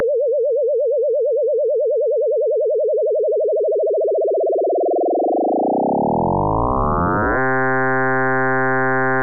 FM-Synthese
Dieser Effekt lässt sich am leichtesten hören, wenn man von einer niedrigen Modulationsfrequenz zu einer hohen automatisiert.
Kommt die ansteigende Modulationsfrequenz in den hörbaren Bereich (ab 20 Hz), interpretiert unser Gehör einen kongruenten, harmonisch komplexen Klang.
FMSynth.wav